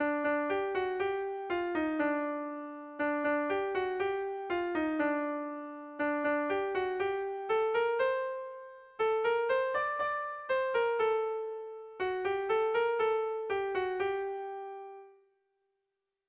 Gabonetakoa
A1A1A2BD